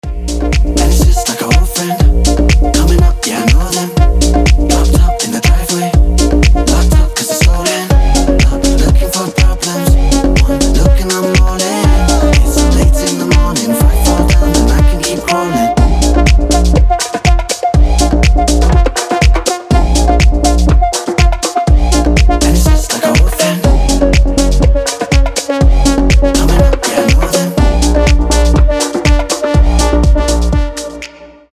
• Качество: 320, Stereo
ритмичные
deep house
Electronic
EDM
house